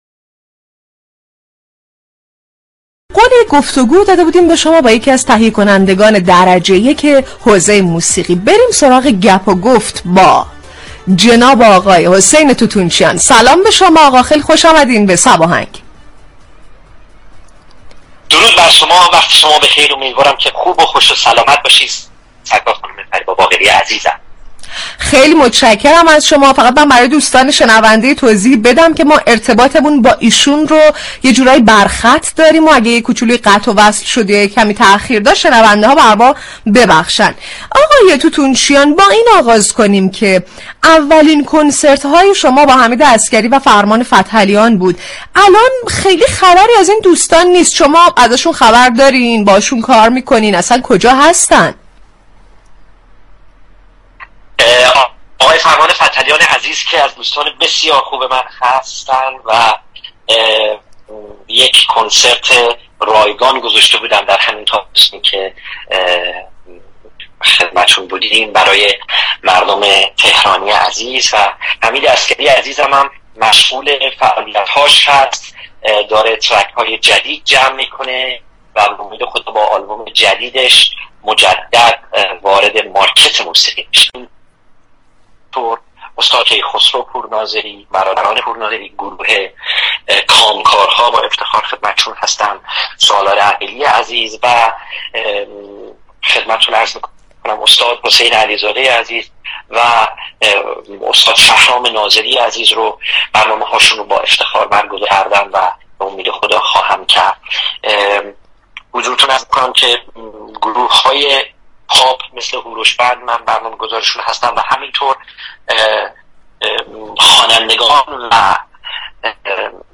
به گزارش روابط عمومی رادیو صبا، «صباهنگ» برنامه موسیقی محور رادیو صبا است كه با پخش ترانه های درخواستی، فضای شادی را برای مخاطبان این شبكه ایجاد می كند.